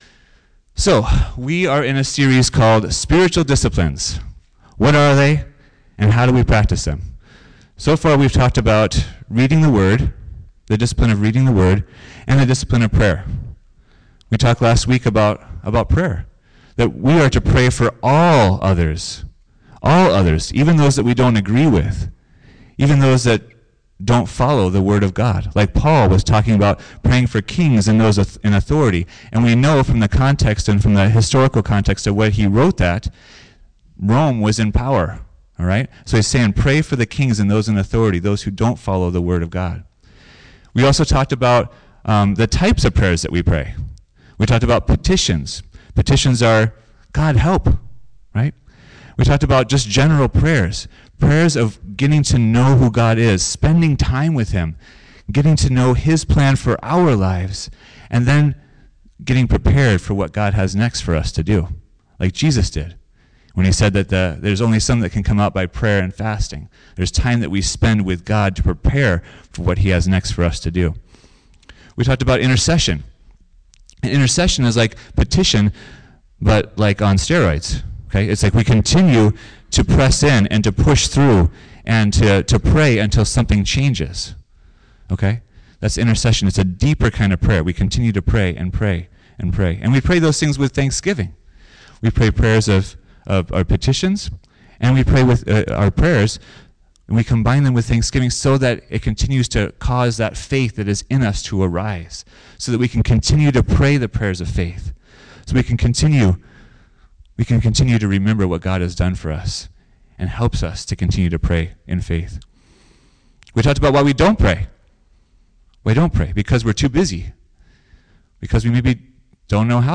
Note: Make sure you take a few minutes at the end of the message to practice spending time in silence with Jesus!